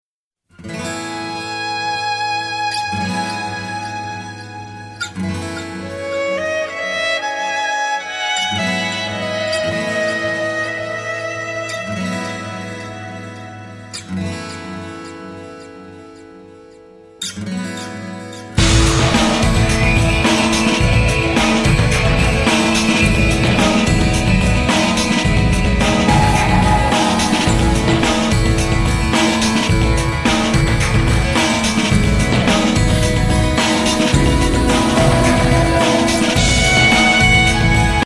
Je fais appelle a votre aide car je ne parviens pas a trouver ces 3- 4 accords derrière le violon, dans le morceau suivant :
Par la même occasion, est ce que vous arrivez à savoir ce qu'il ce joue dans la deuxieme partie de la chanson (je parle du delay en derriere)